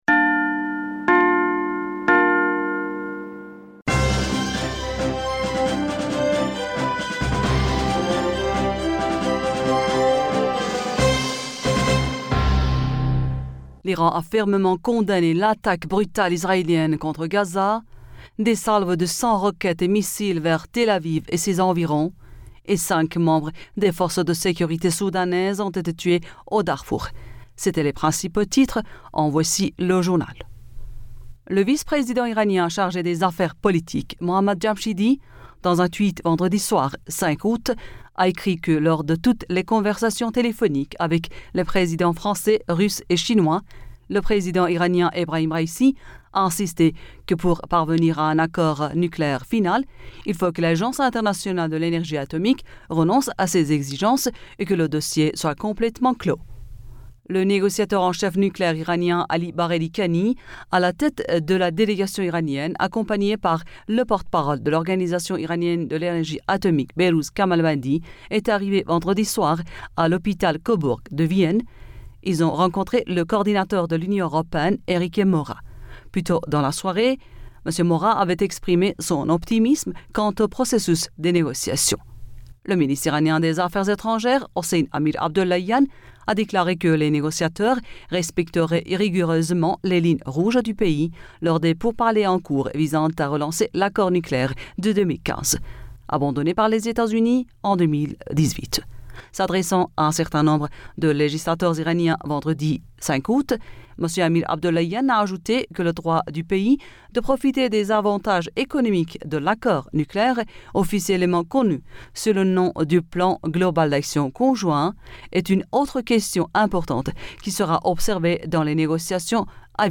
Bulletin d'information Du 06 Aoùt